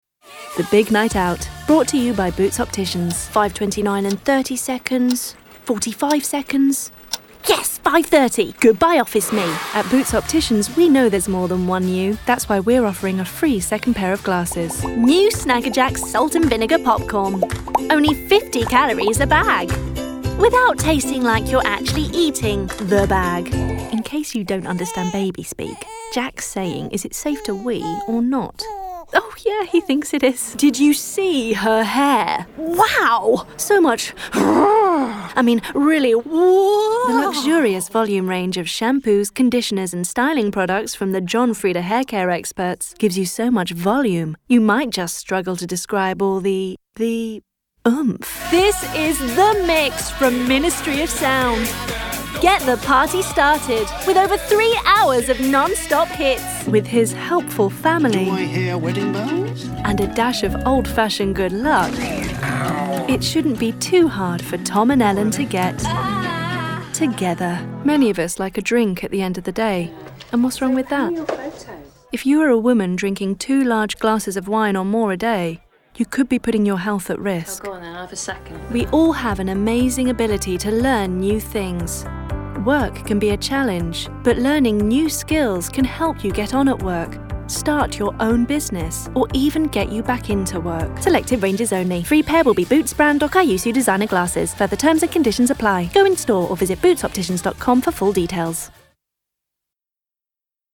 • Female
• Estuary English
• London
• Standard English R P
• Standard U S